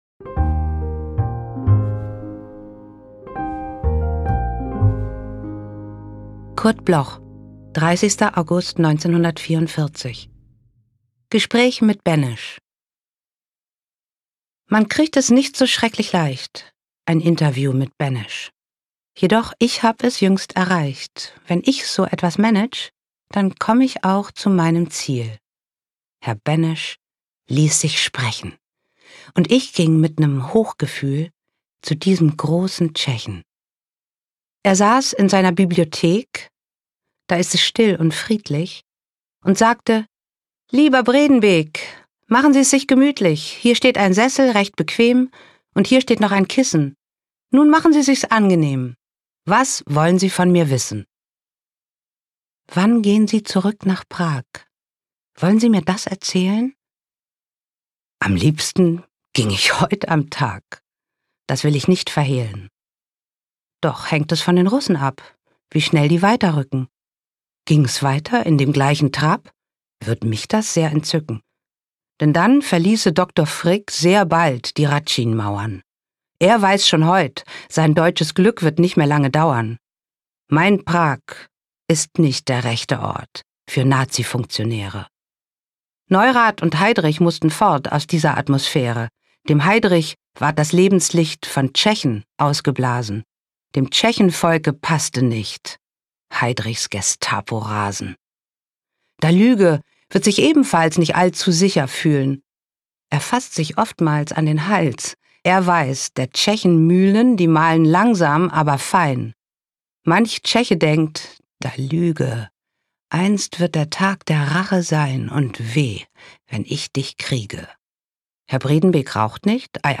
performed by Maria Schrader
Maria-Schrader-Gespraech-mit-Benesch-mit-Musik.m4a